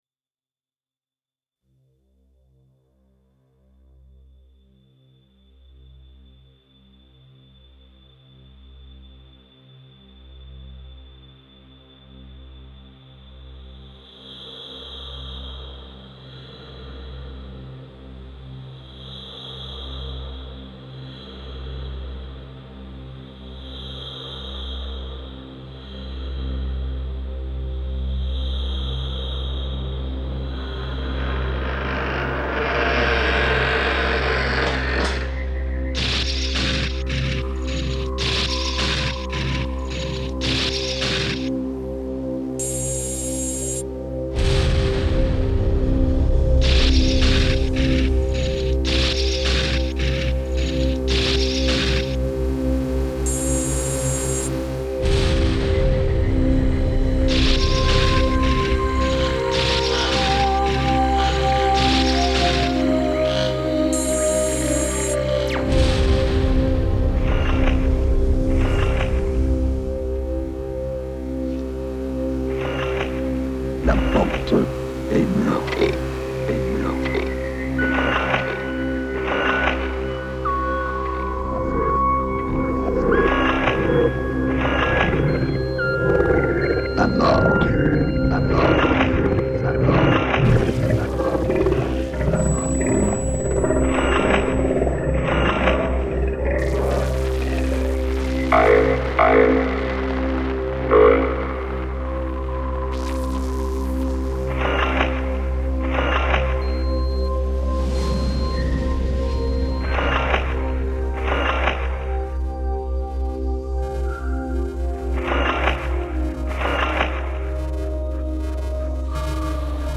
pure sensitive electronic music
Grinding sounds straight out from the boring hell of europe.